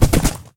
horse_gallop2.ogg